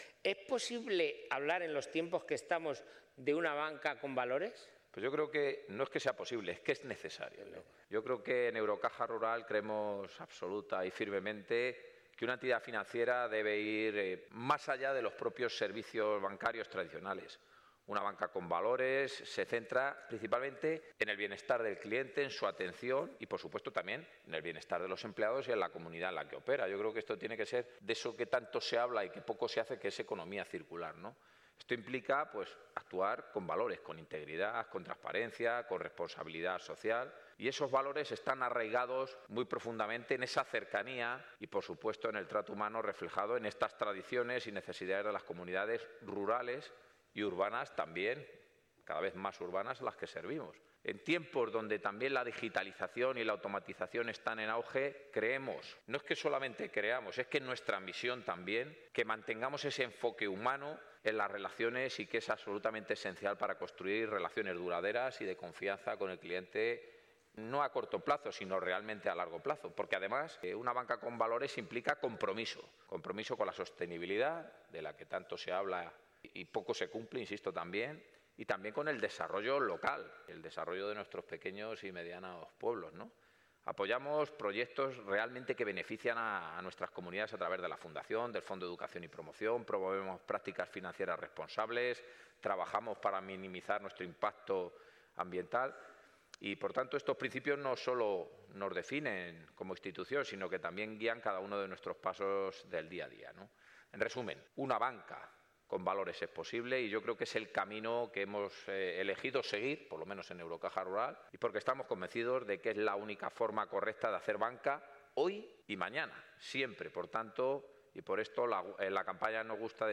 Los distintos spots han sido rodados en una oficina real de Eurocaja Rural.